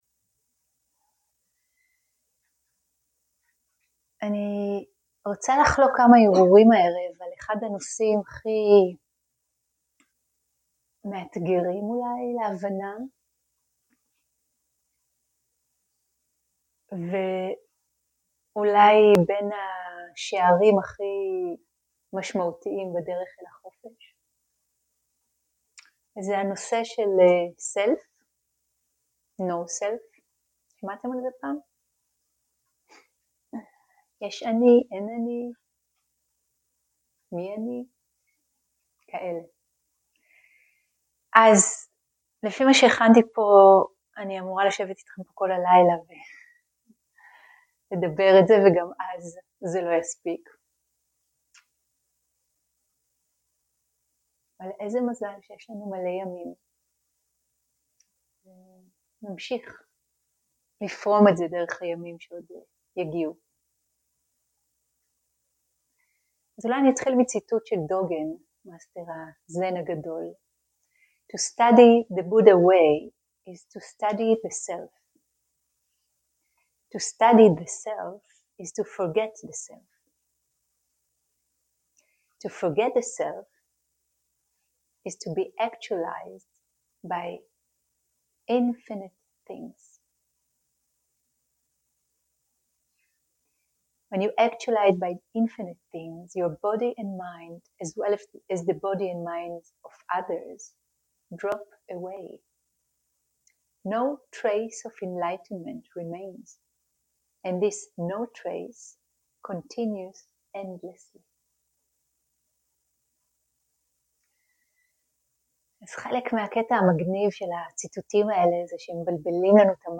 שיחת דהרמה - סלף ונוט סלף - שיחה על העצמי והלא עצמי
סוג ההקלטה: שיחות דהרמה